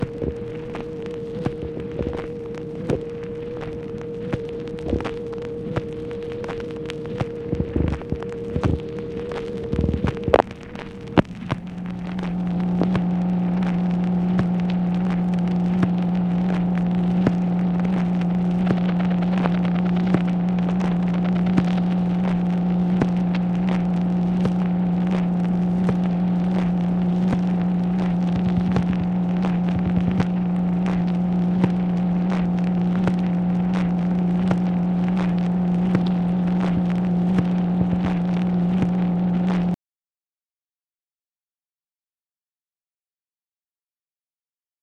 MACHINE NOISE, January 17, 1966
Secret White House Tapes | Lyndon B. Johnson Presidency